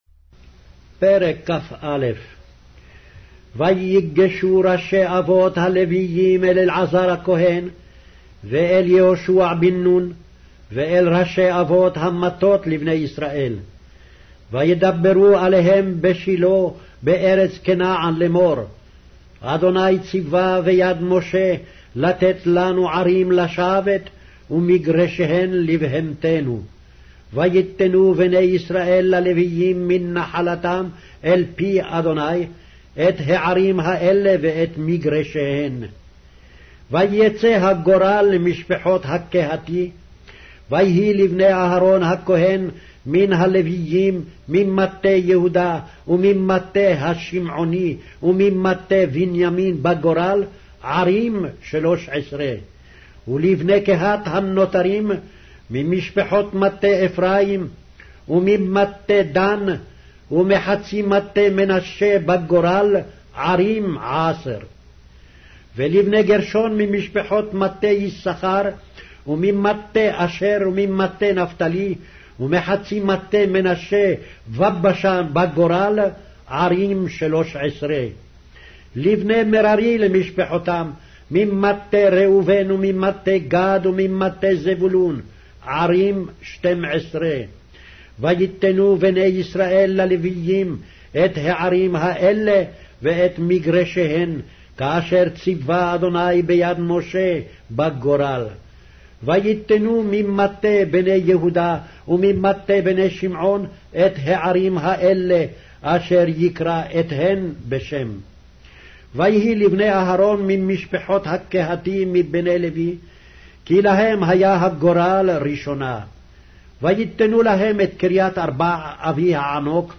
Hebrew Audio Bible - Joshua 12 in Akjv bible version